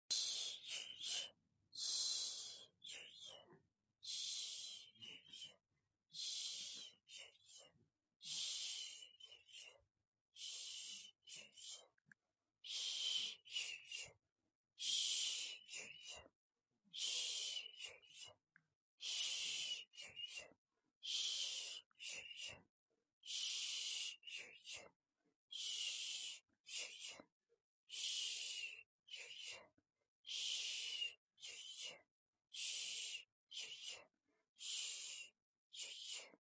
Real human voice
Shh Shh.mp3